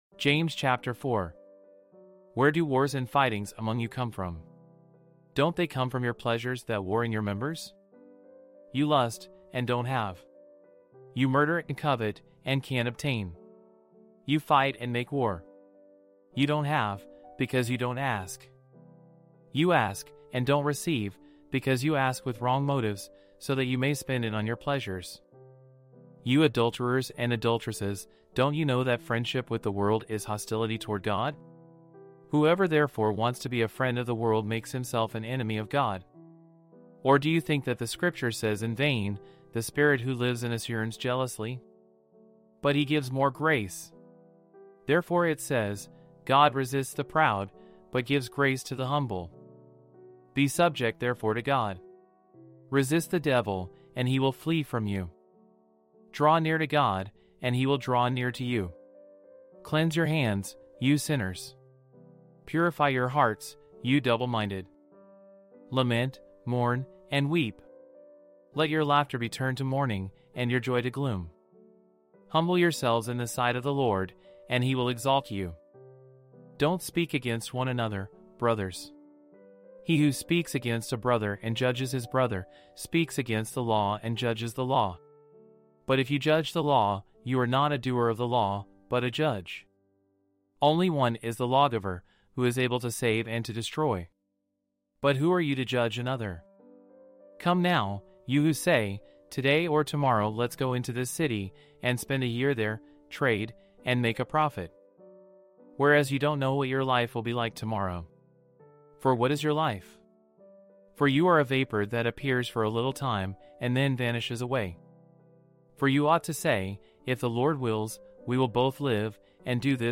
Audio Bible with Text